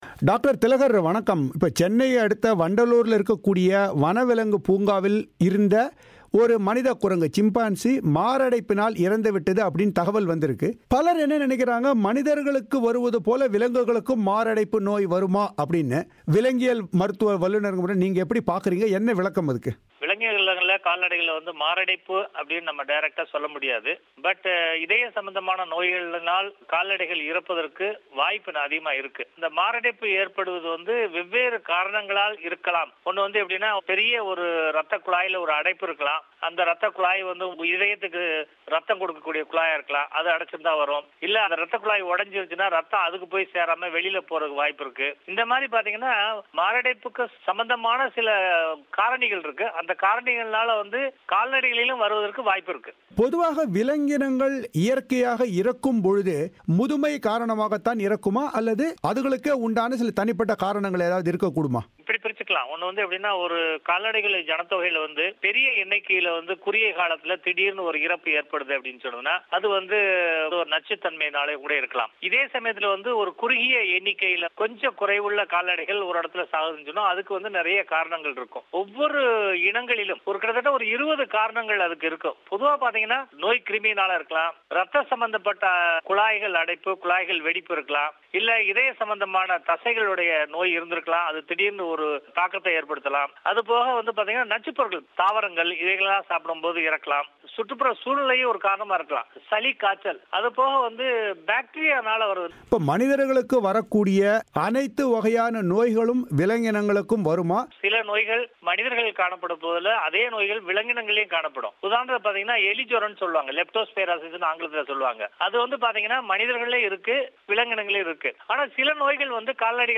அவர் பிபிசி தமிழோசையிடம் தெரிவித்த கருத்துக்களை இங்கே கேட்கலாம்.